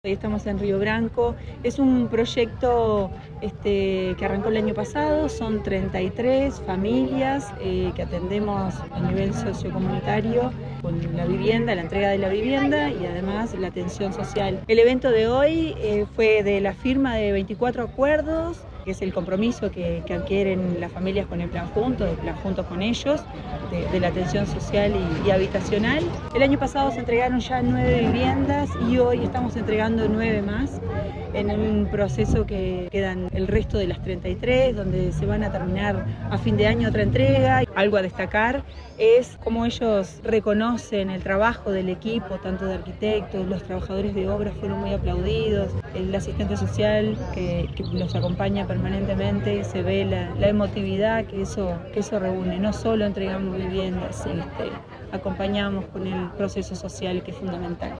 Declaraciones de la coordinadora general de plan Juntos, Inés Sarries